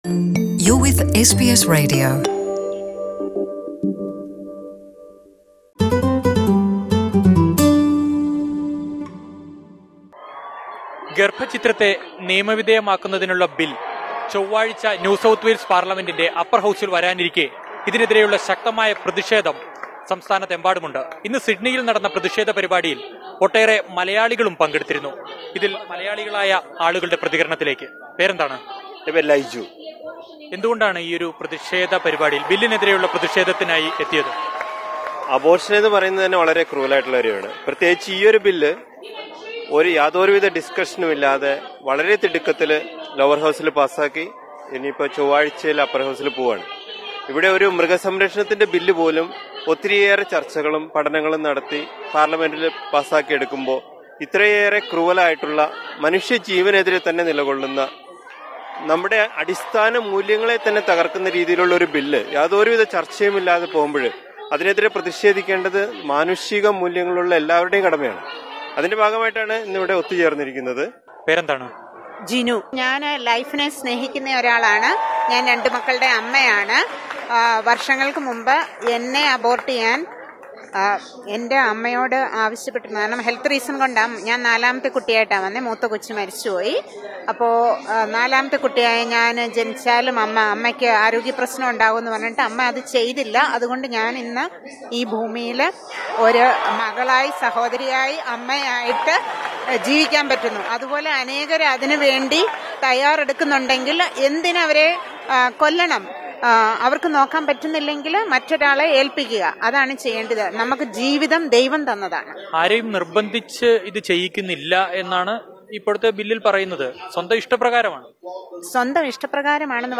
Listen to the opinions of Malayalees in NSW who particpate in the anti-abortion rally.